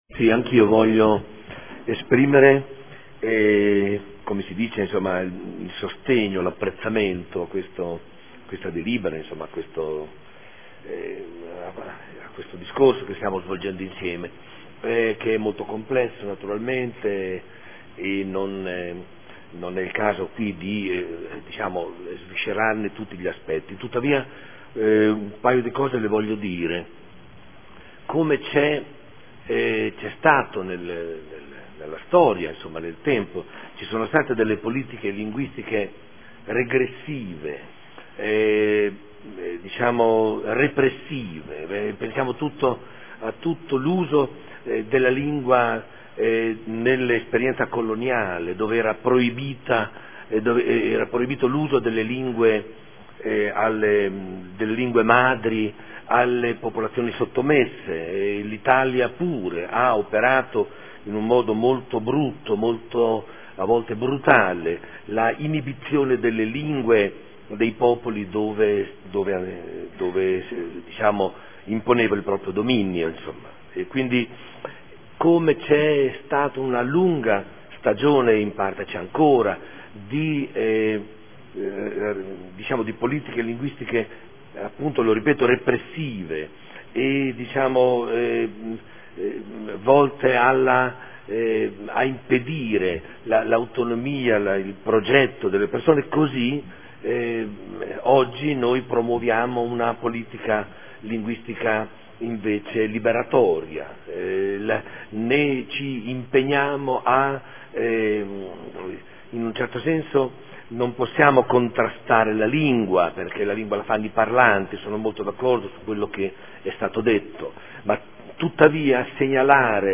Domenico Campana — Sito Audio Consiglio Comunale